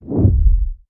Звук стремительного перемещения